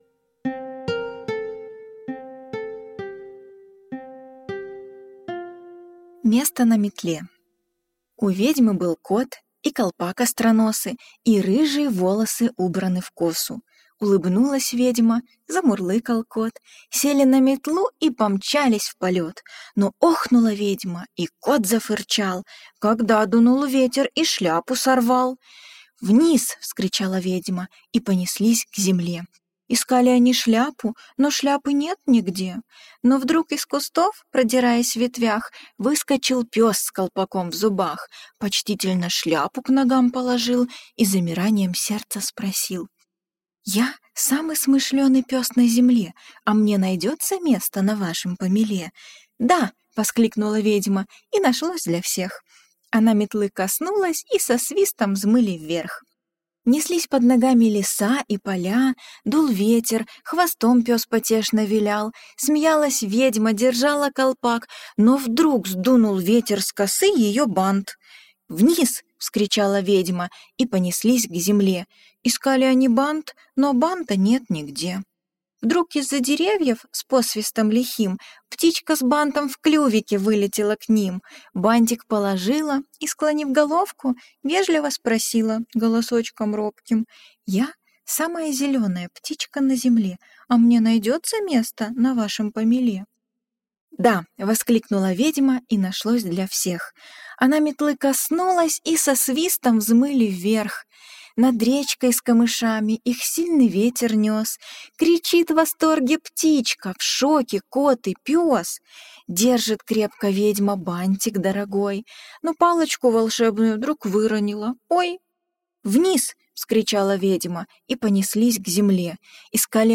Верхом на помеле - аудиосказка Джулии Дональдсон - слушать онлайн